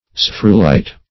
\Sphaer"u*lite\